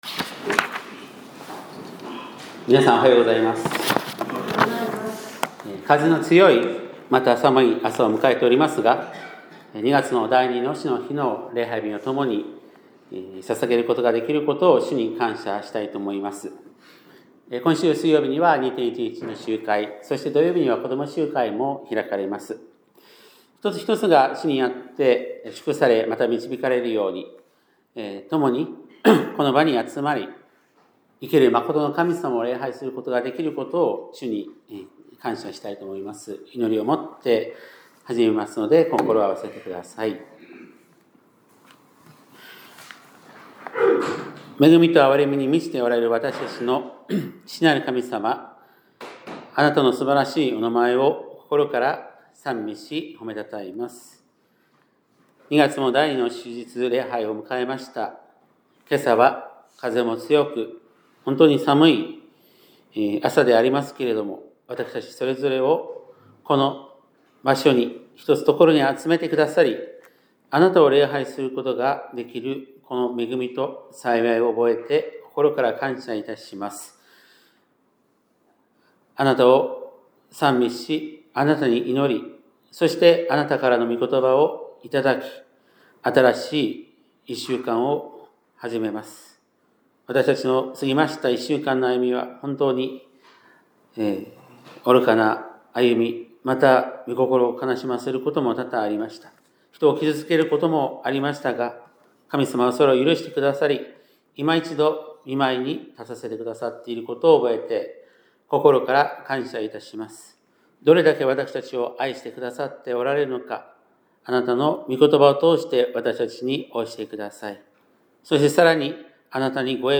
2026年2月8日（日）礼拝メッセージ - 香川県高松市のキリスト教会
2026年2月8日（日）礼拝メッセージ